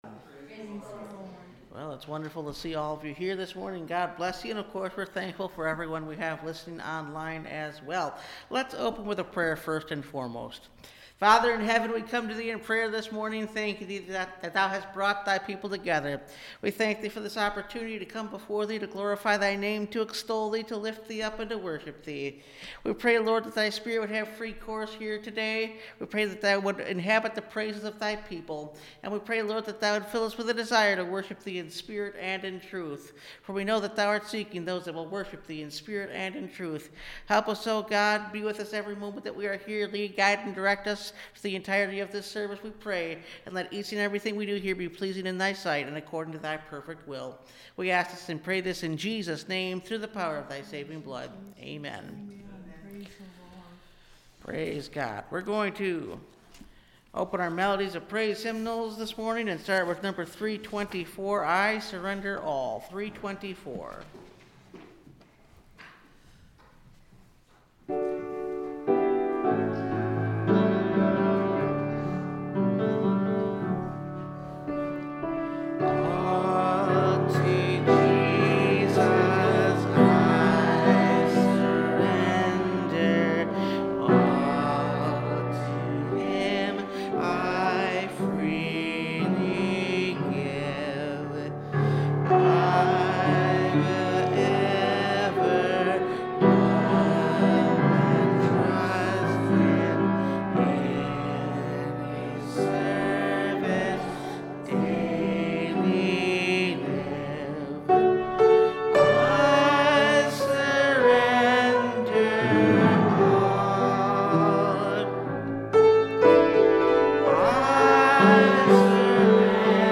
The Glory of God – Last Trumpet Ministries – Truth Tabernacle – Sermon Library